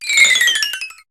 Cri de Crikzik dans Pokémon HOME.